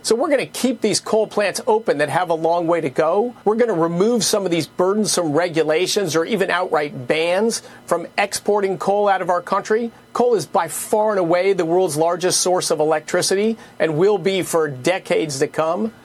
President Trump continues to boast on “Beautiful Clean Coal,” investing over $600 million dollars in the coal industry including opening federal lands for mining, keeping coal plants open and other steps modernizing the industry. U.S. Energy Secretary Christopher Wright says that coal is critical and has a long future ahead…